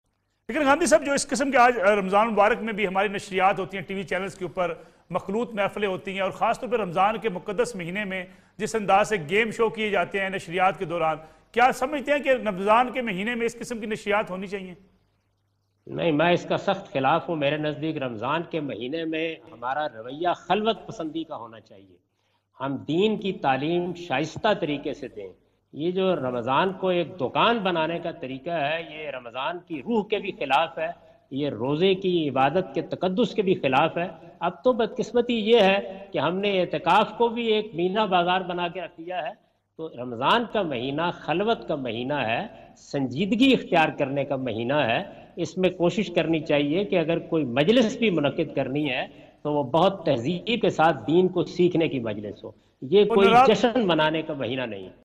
In this program Javed Ahmad Ghamidi answer the question about "Siprit of Ramadan and Tv Programs" on Neo News.
جاوید احمد غامدی نیو نیوزکے پروگرام میں "رمضان المبارک کا مقصد اور ٹی وی پروگرامز"سے متعلق سوال کا جواب دے رہے ہیں۔